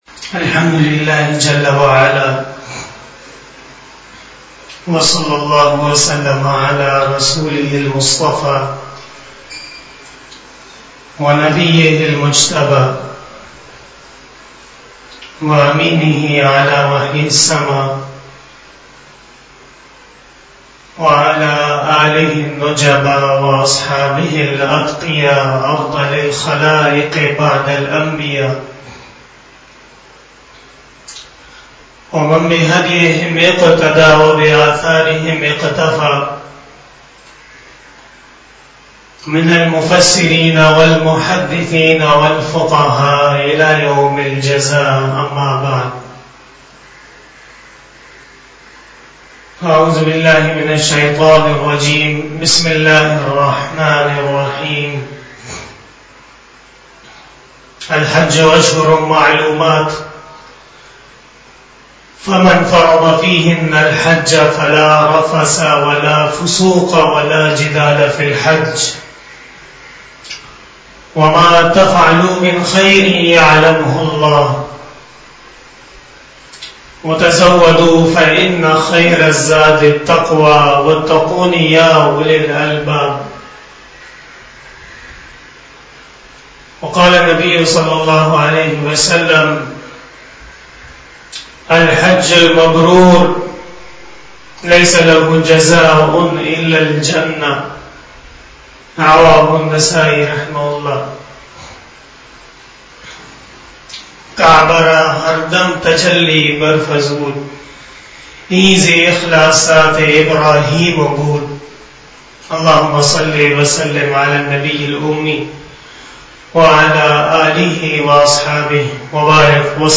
29 BAYAN E JUMA TUL MUBARAK 16 July 2021 (05 Zil-Hajj 1442H)
بیان جمعۃ المبارک